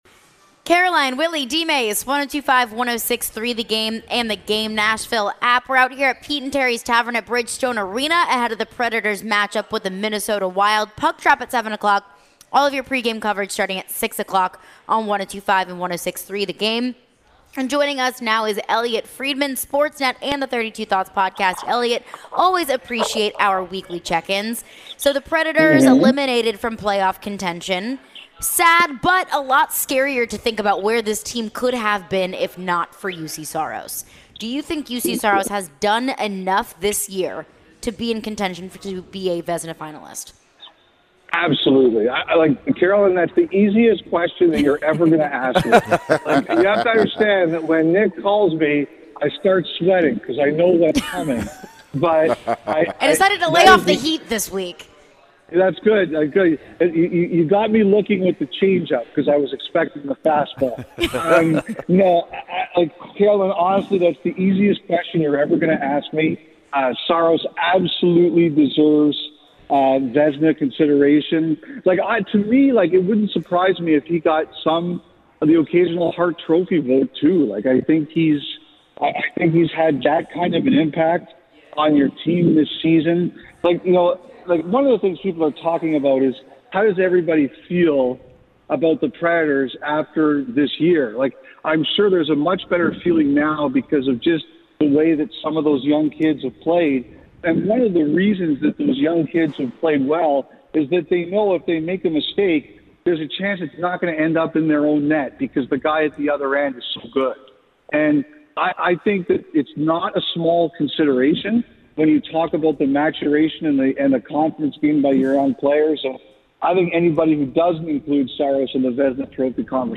Elliotte Friedman Interview (4-13-23)